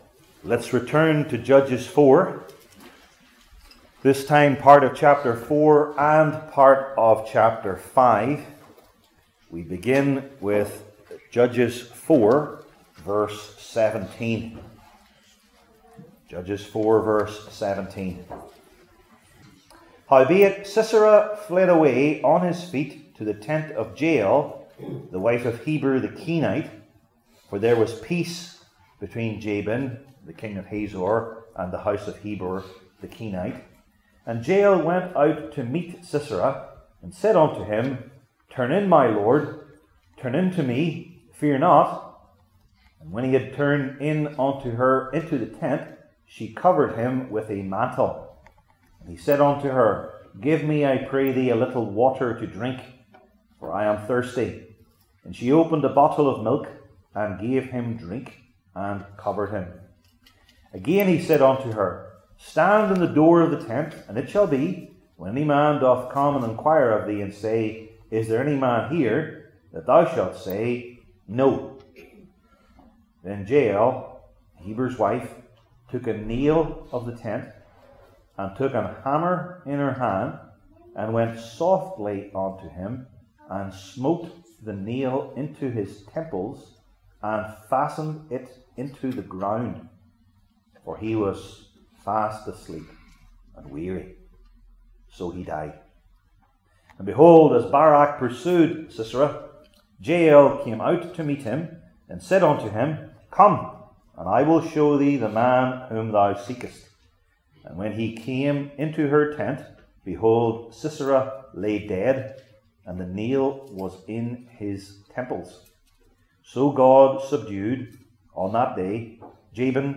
Old Testament Sermon Series I. The Unusual Weapon II.